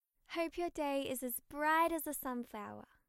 ハートの部分を押すと、メンバーの音声メッセージが流れます♪ 特別感が味わえるアイテムです。